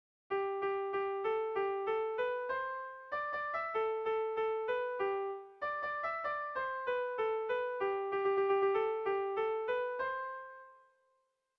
Sehaskakoa
Kopla handia
8A / 8A / 10 / 8A (hg) | 8A / 8A / 18A (ip)
ABD